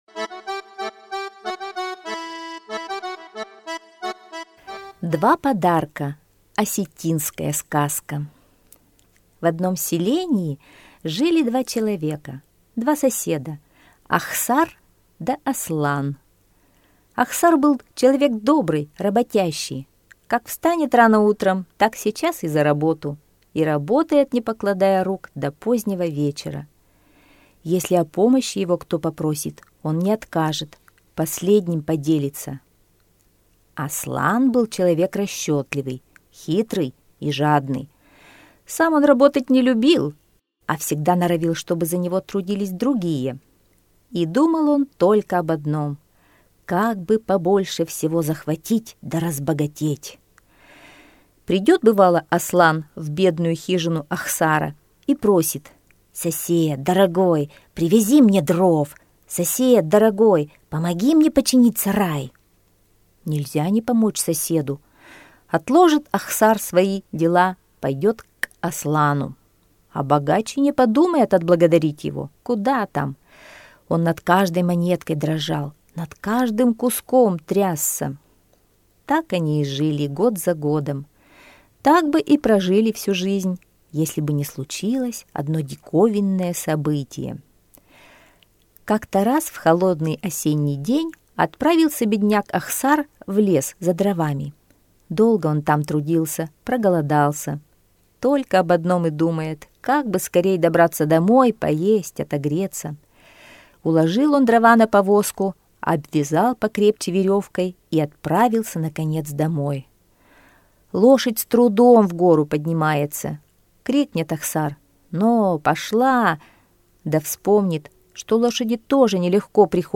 Два подарка - осетинская аудиосказка - слушать онлайн